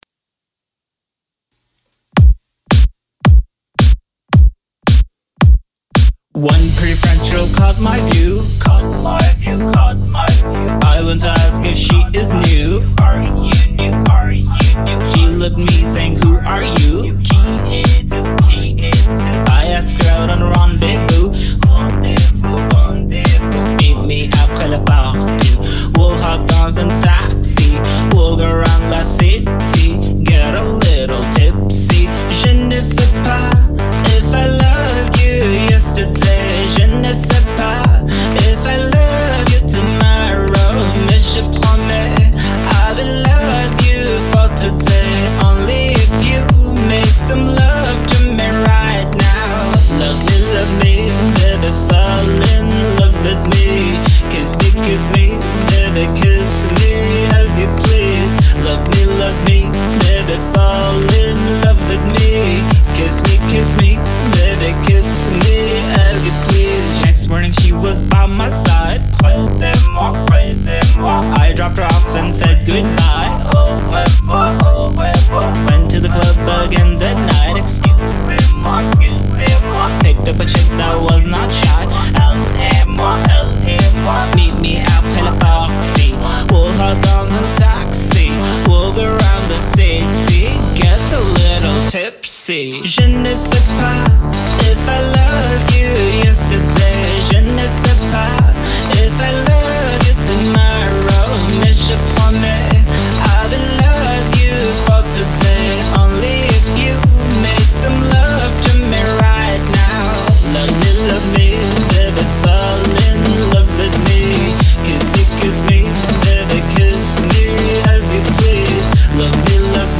English-French pop song